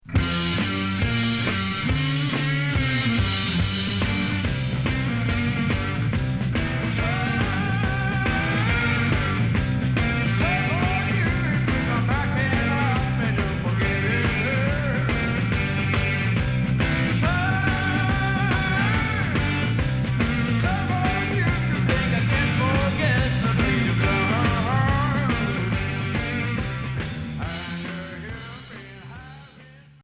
Heavy rock blues